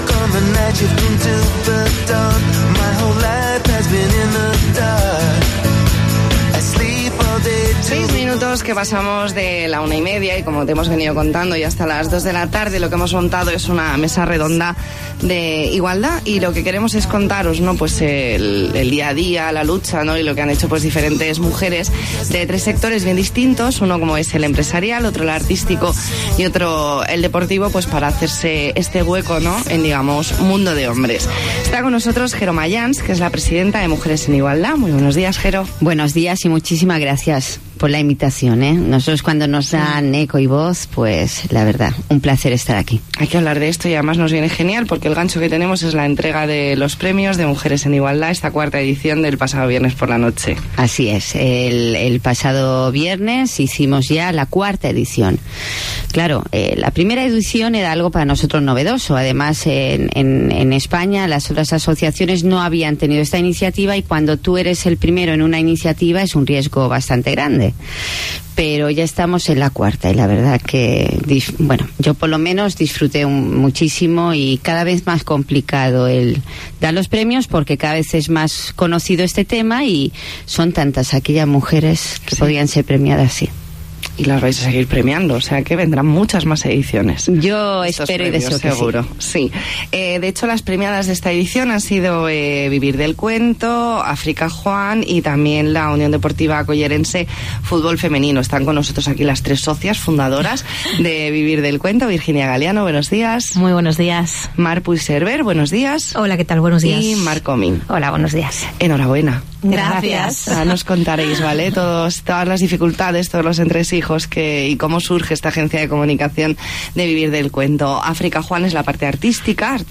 Mesa redonda sobre igualdad y conciliación